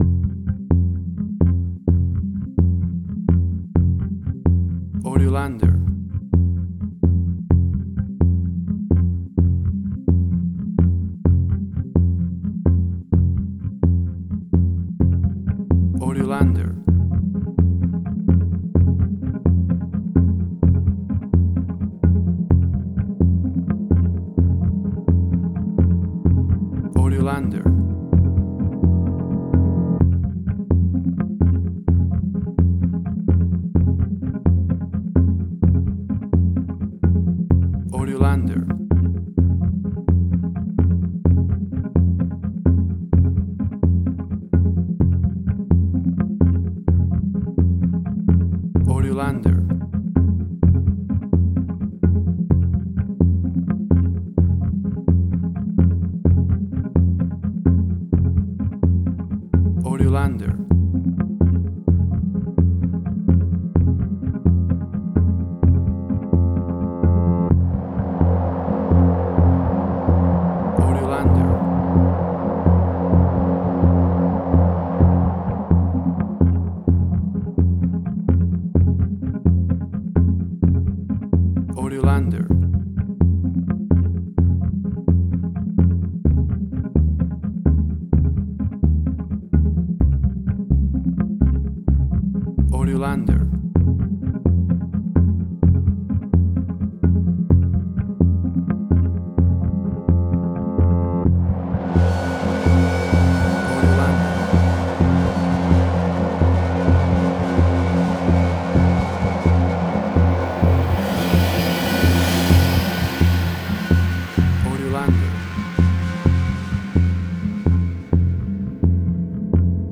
Post-Electronic.
Tempo (BPM): 64